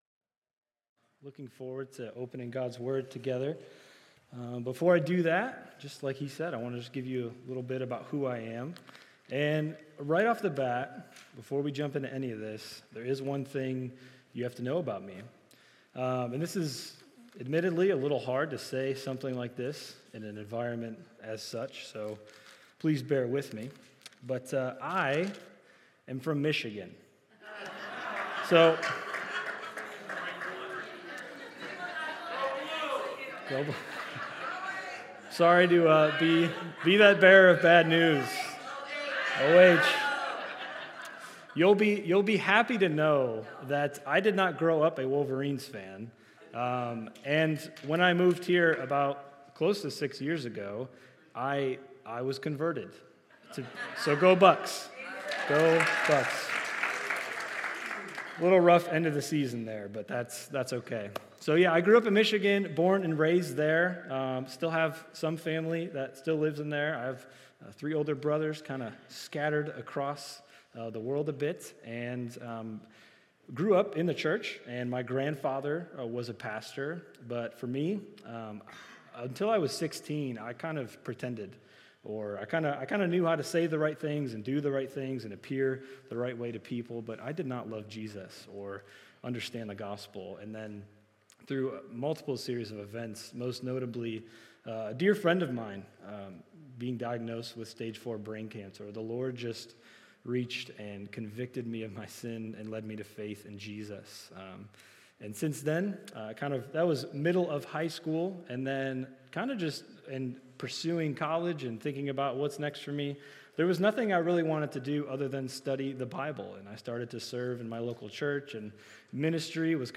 SERMONS -